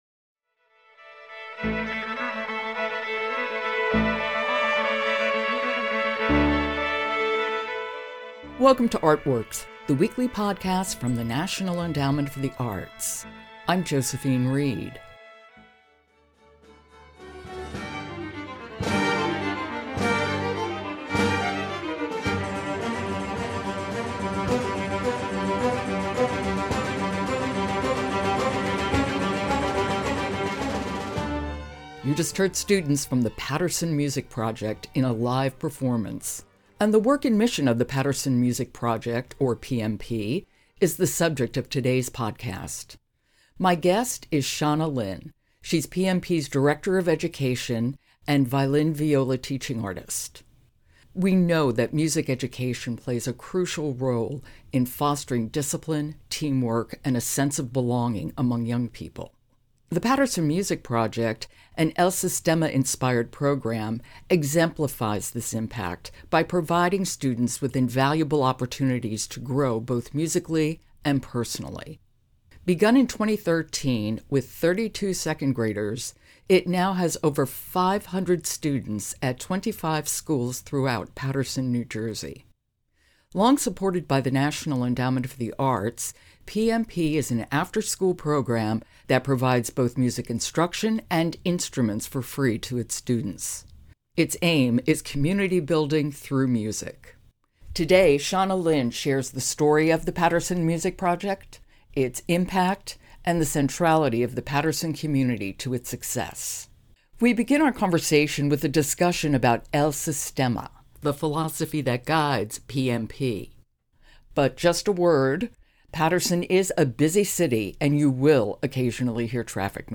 Excerpts from the live performance performed in New Jersey on May 18, 2024 by students of the Paterson Music Project